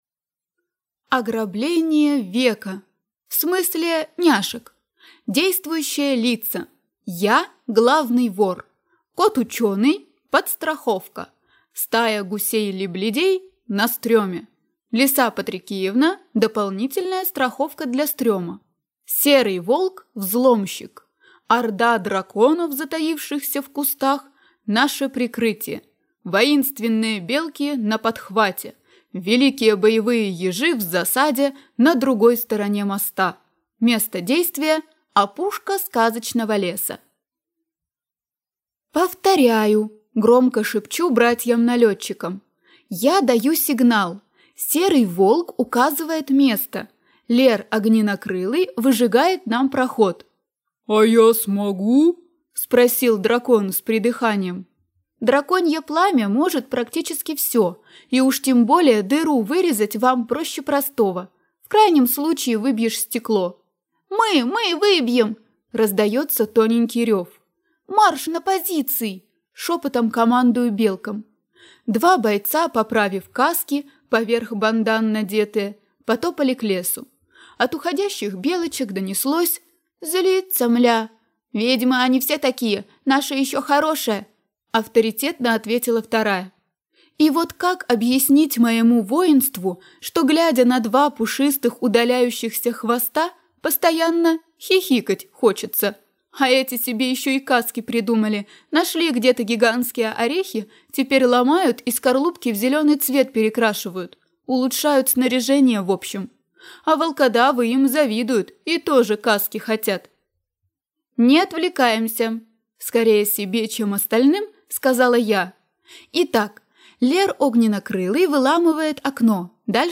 Аудиокнига «Будь моей ведьмой» в интернет-магазине КнигоПоиск ✅ Фэнтези в аудиоформате ✅ Скачать Будь моей ведьмой в mp3 или слушать онлайн